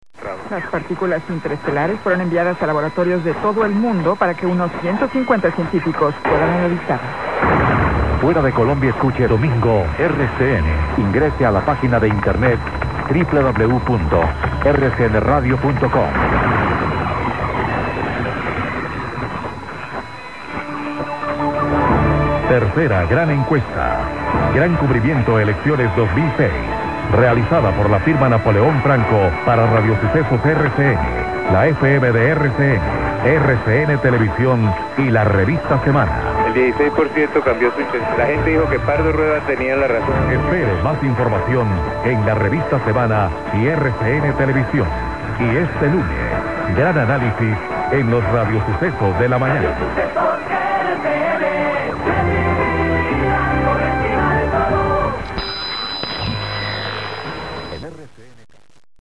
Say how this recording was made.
Here are some loggings of MW/SW-stations heard in Paimio (not all stations listed here) mp3 "Erre-Ce-Ene" / Good signal !